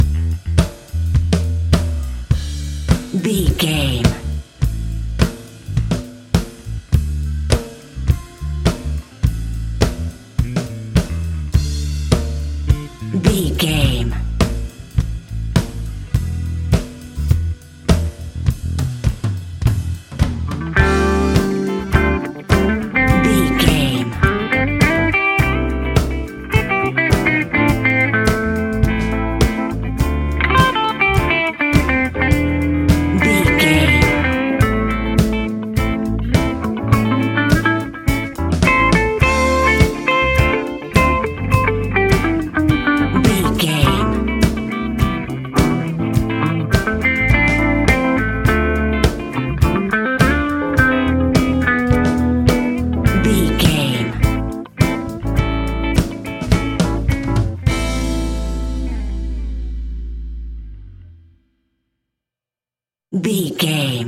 Ionian/Major
house
electro dance
synths
techno
trance